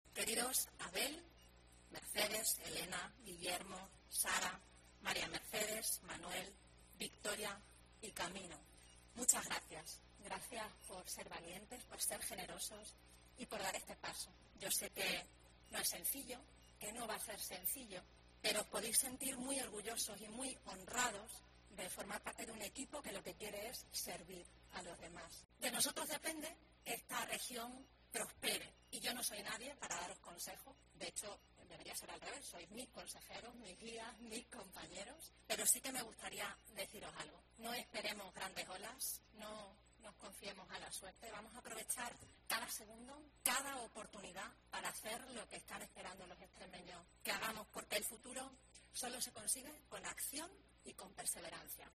Guardiola ha cerrado el acto de toma de posesión de su equipo, que se ha celebrado en el claustro de la Presidencia de la Junta de Extremadura.